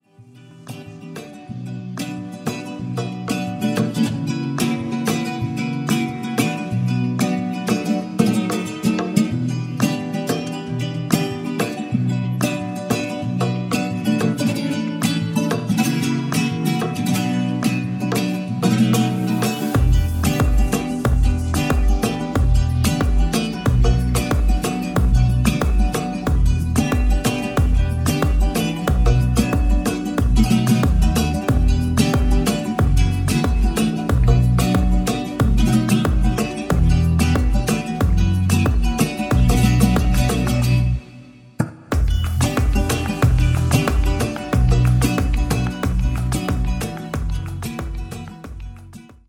(version manouche)